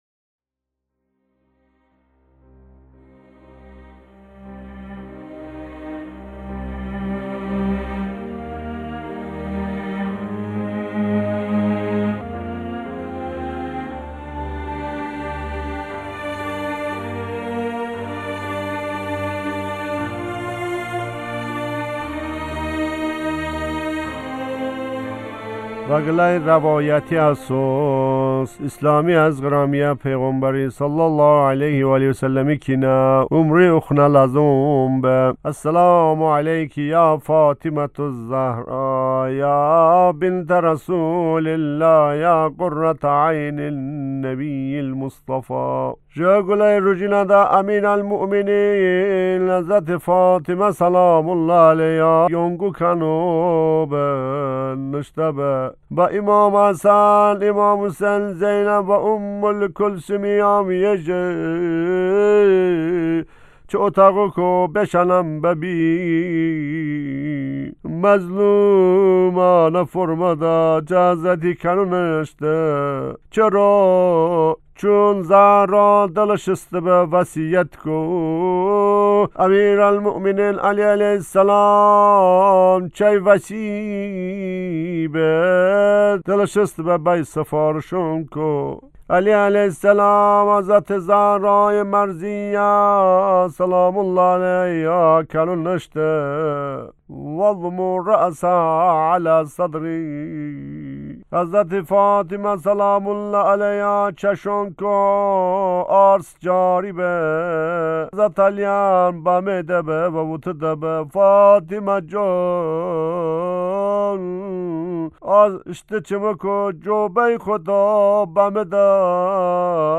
Həzrəte Fatimə(s) matəmədə(tolışə rozə) ...